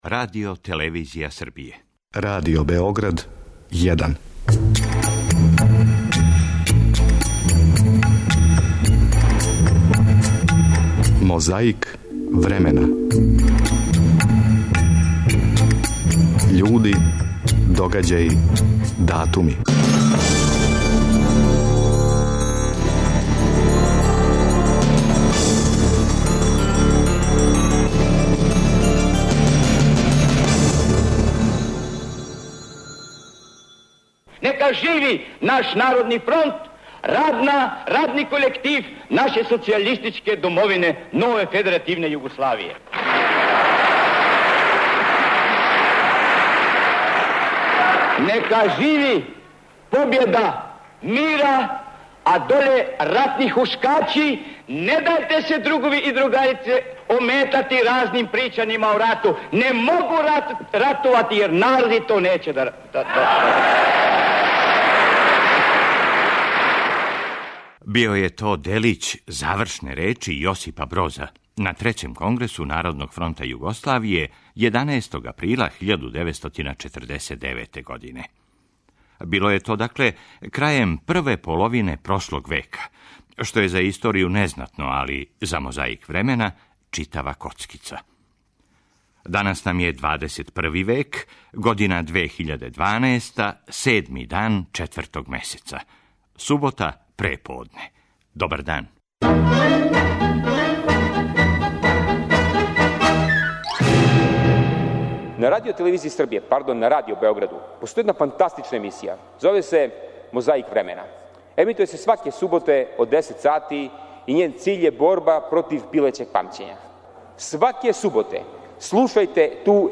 А у броду - Јуриј Алексејевич Гагарин! У нашој коцкици космонаут лети, прича, пева.